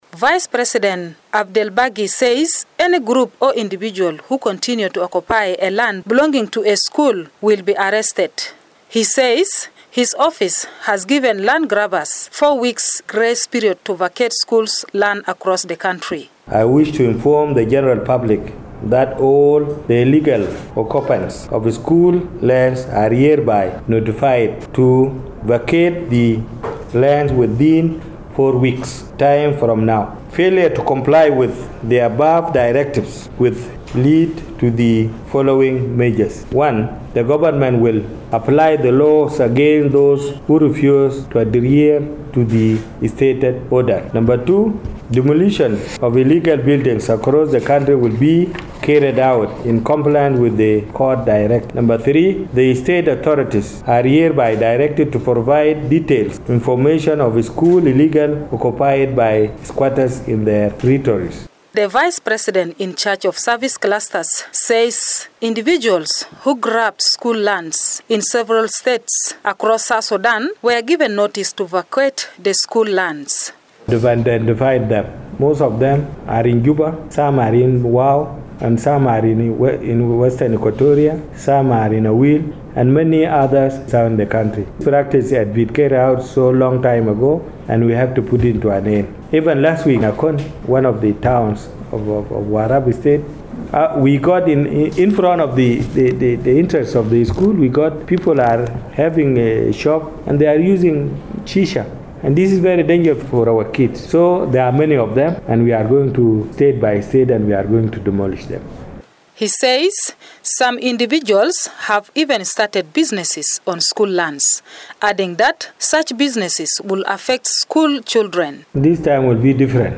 reports from Juba.